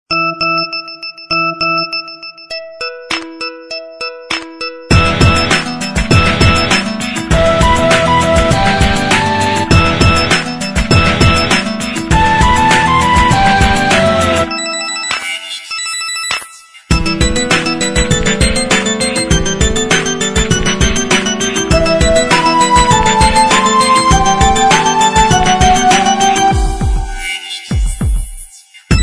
SMS рингтоны